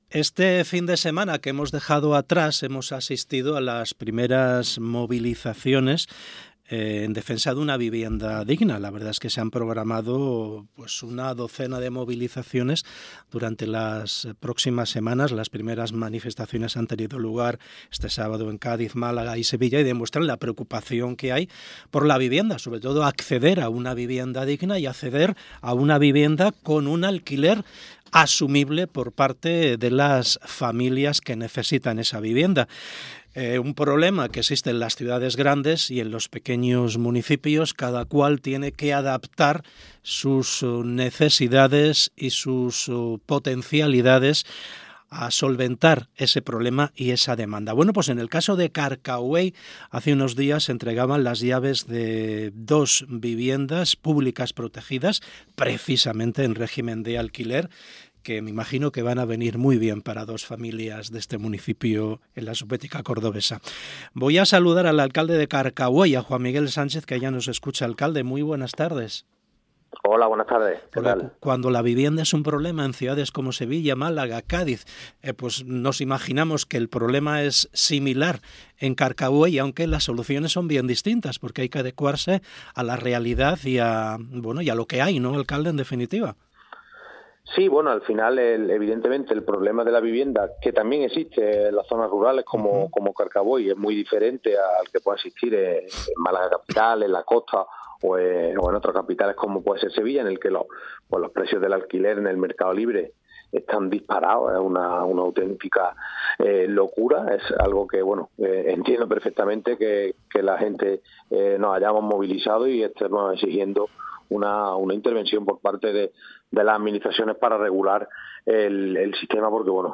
ENTREVISTA | Juan Miguel Sánchez, Alcalde de Carcabuey